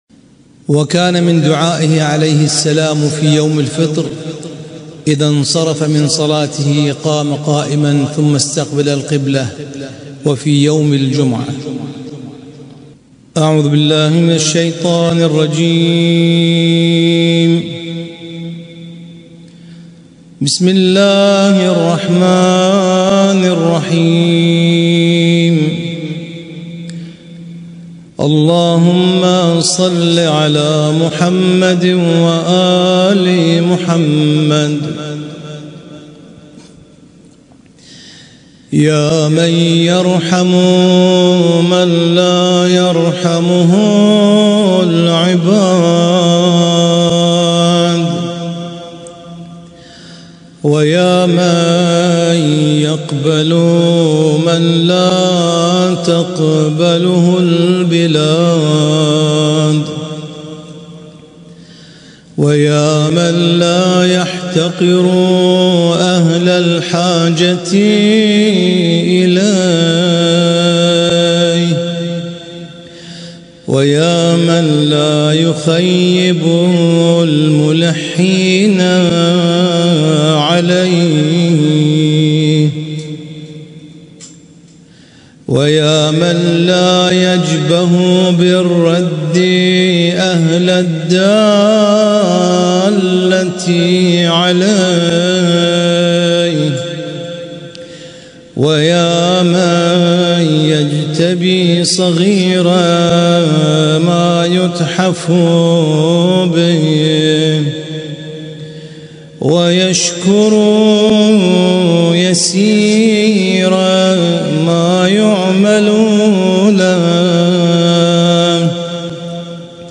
اسم التصنيف: المـكتبة الصــوتيه >> الصحيفة السجادية >> الادعية السجادية